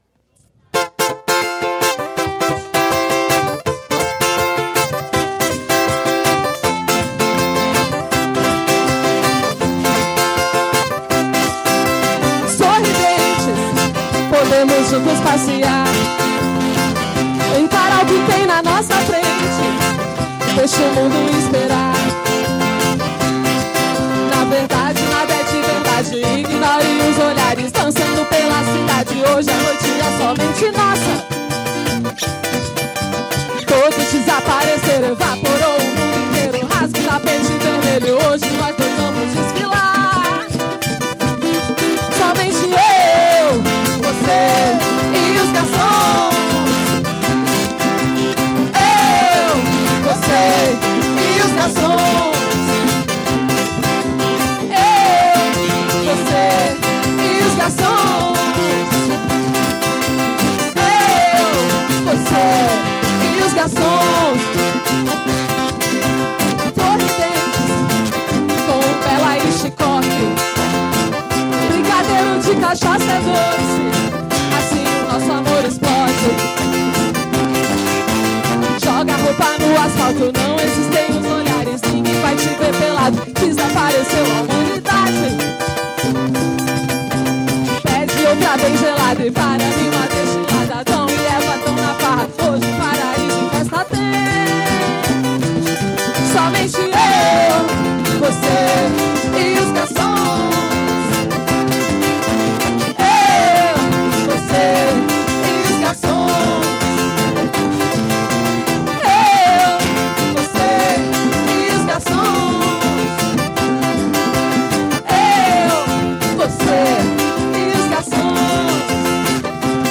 Aqui, alguns momentos dessa edição especial que reuniu os encontros ATIVISMO DIGITAL e a MOSTRA de CULTURA E SAÚDE: